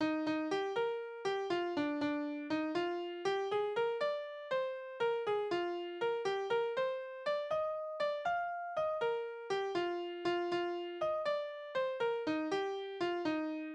Tonart: Es-Dur
Tonumfang: kleine Dezime
Besetzung: vokal
Anmerkung: Die Taktart ist nicht eindeutig, aber es scheint sich um einen ungeraden Achtel-Takt zu handeln (3/8 oder 6/8). Die einzelnen Takte sind unterschiedlich lang.